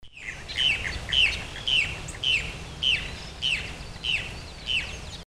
Cacholote Castaño (Pseudoseisura lophotes)
Nombre en inglés: Brown Cacholote
Fase de la vida: Adulto
Localidad o área protegida: Reserva privada Don Felix y Sacha Juan
Condición: Silvestre
Certeza: Fotografiada, Vocalización Grabada